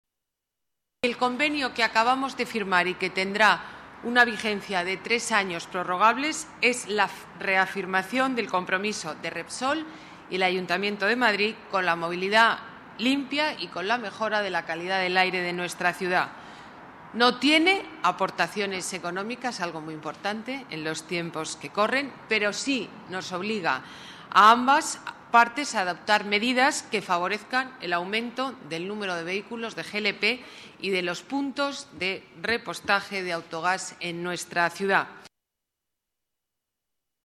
Nueva ventana:Declaraciones alcaldesa Madrid, Ana Botella: convenio Repsol, responsabilidad con movilidad limpia